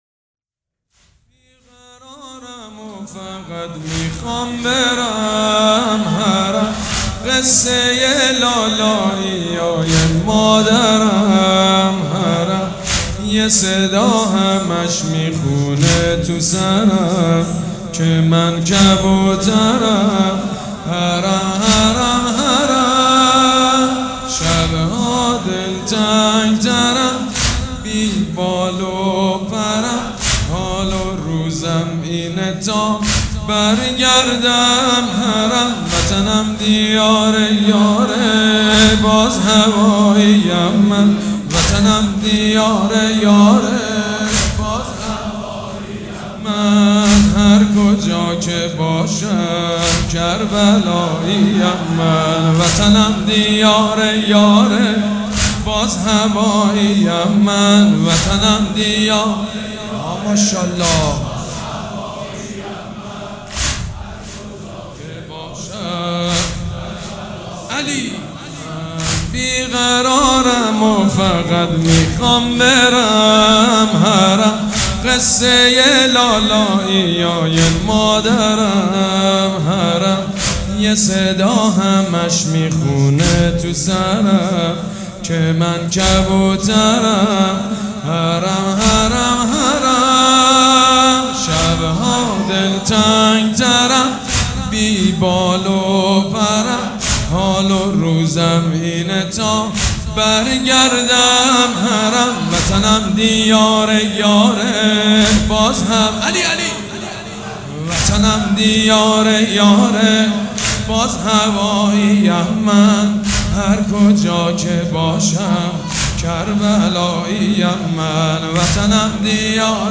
مداح
مراسم عزاداری شب سوم